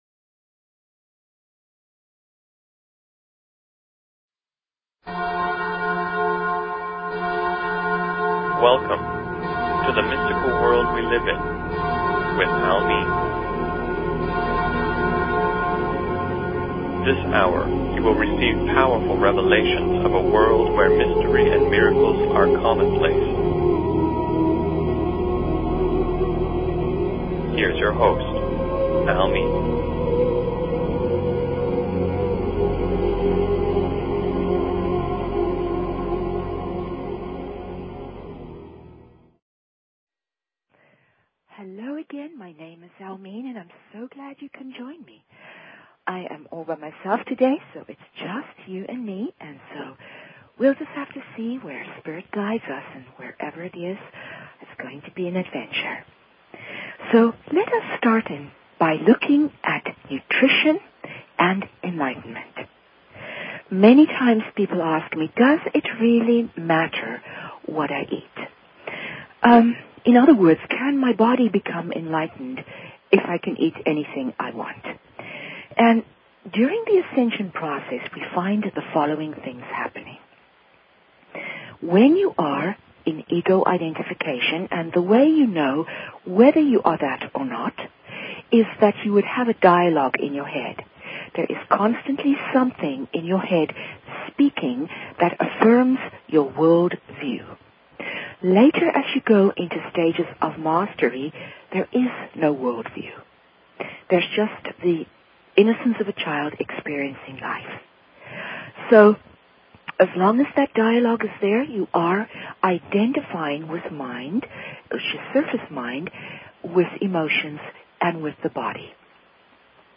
Talk Show Episode, Audio Podcast, The_Mystical_World_we_live_in and Courtesy of BBS Radio on , show guests , about , categorized as